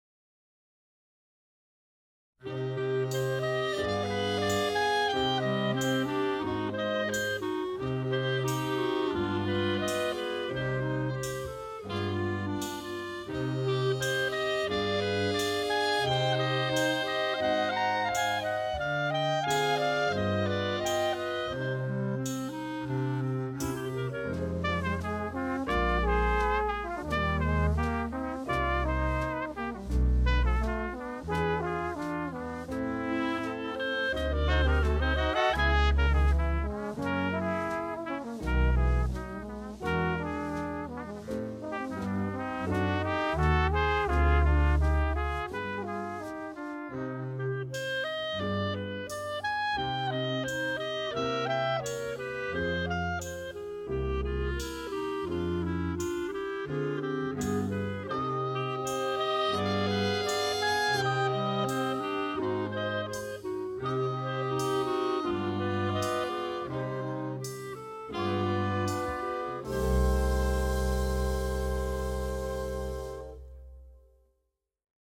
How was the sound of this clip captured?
Recorded Red Gables Studio January 2015